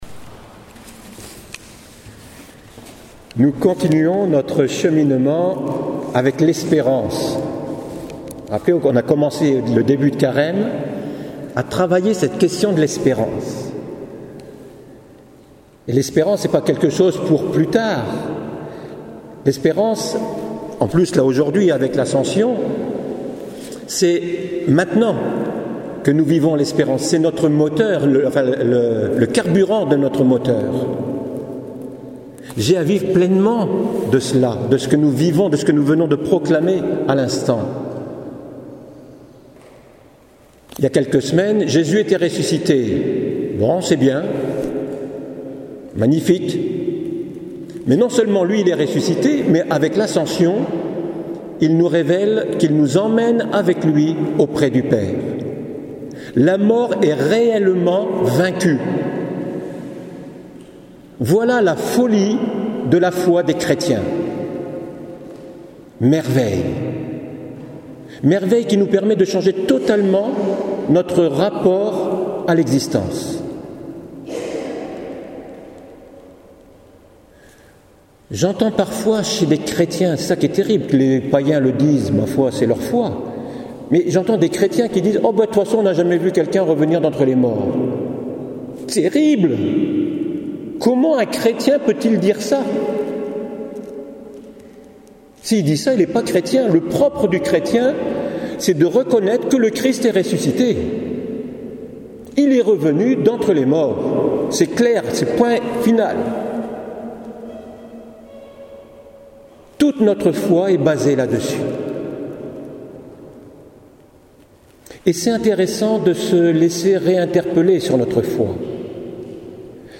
voici l’homélie de ce jour ascension éternelle